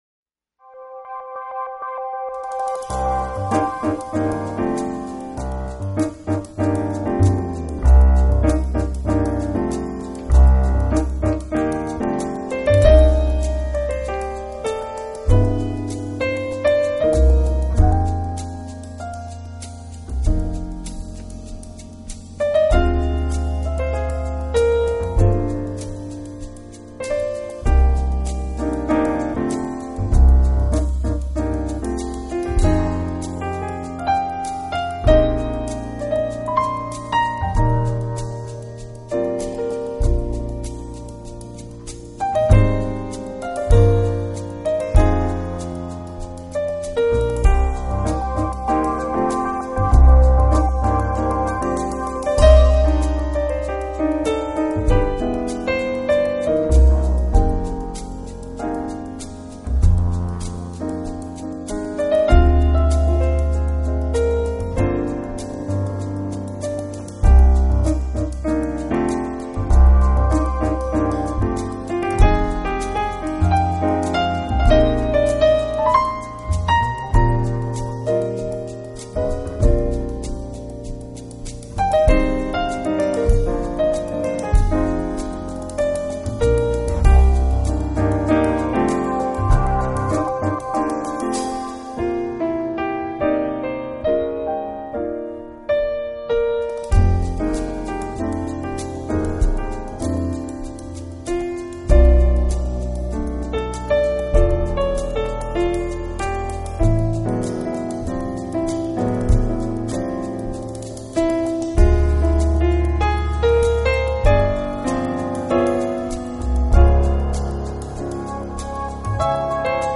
Genre: Jazz / Smooth Jazz